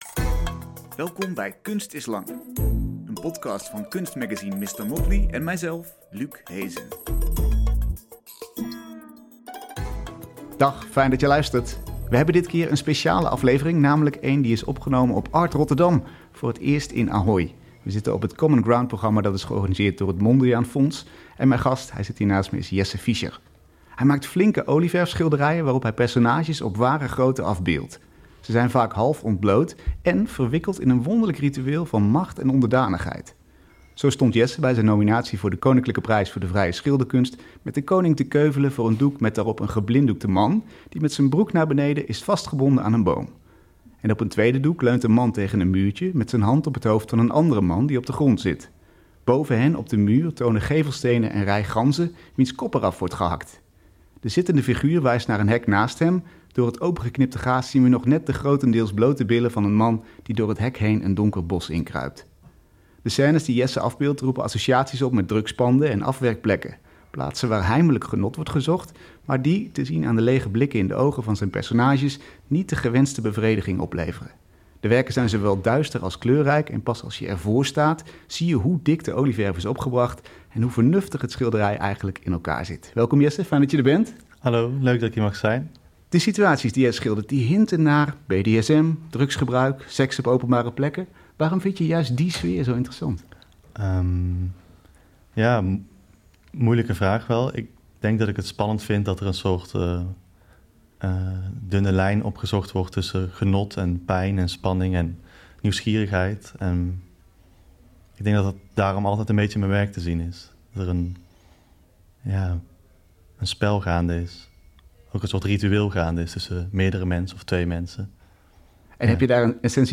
Deze week hoor je de opname die we vrijdag 28 maart maakten voor een live publiek, op Art Rotterdam.